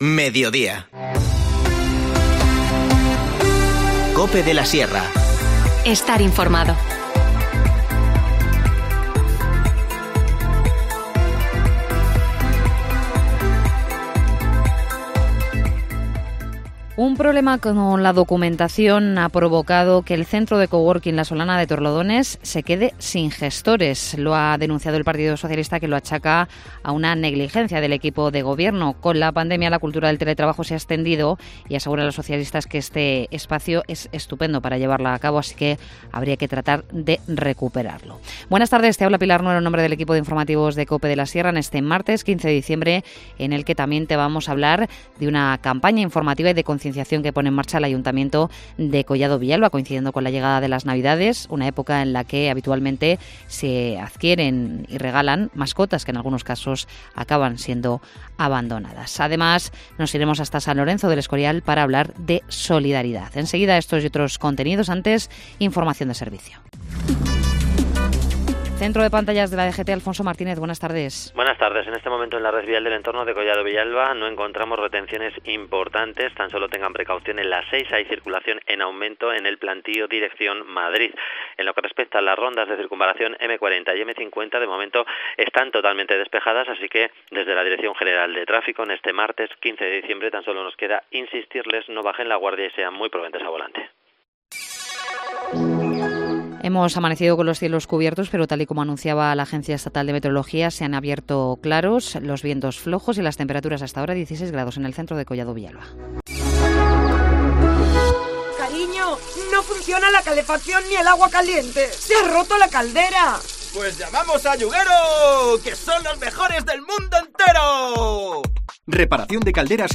Informativo Mediodía 15 diciembre